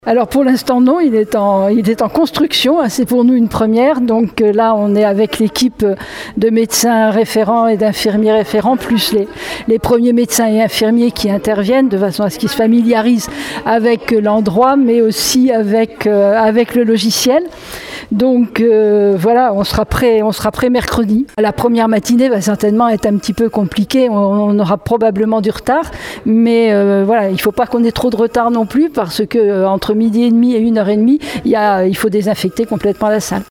Réunion préparatoire hier après-midi au centre de vaccination de Surgères.